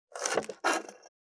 479切る,包丁,厨房,台所,野菜切る,咀嚼音,ナイフ,調理音,まな板の上,料理,
効果音厨房/台所/レストラン/kitchen食器食材